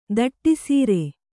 ♪ daṭṭi sīre